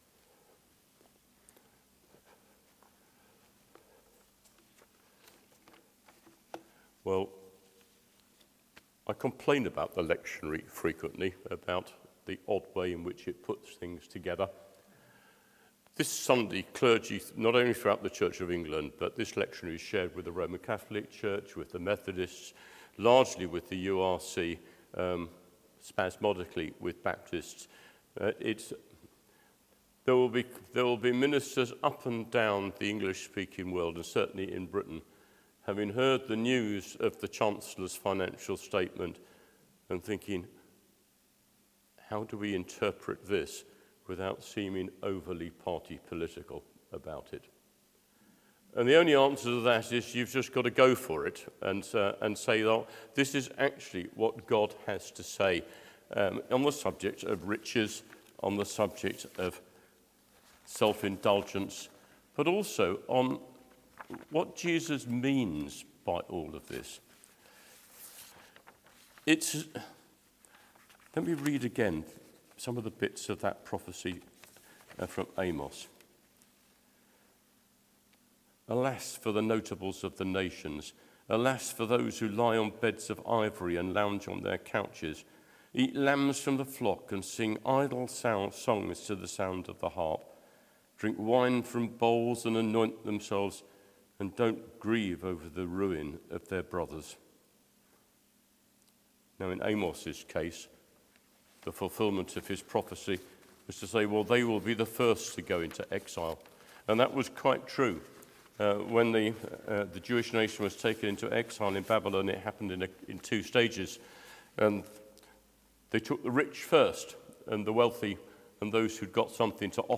Sermon: | St Paul + St Stephen Gloucester